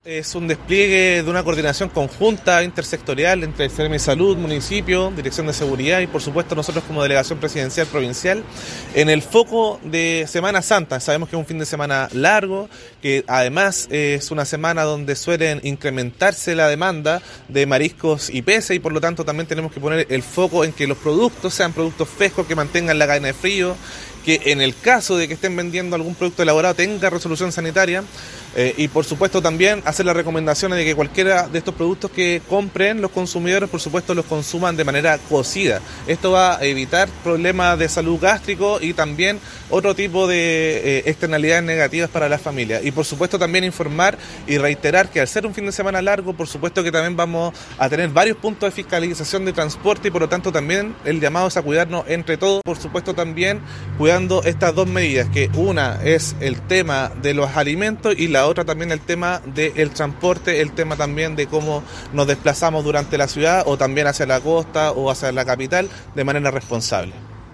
se efectuó un punto de prensa en el frontis de Delegación Presidencial Provincial de Melipilla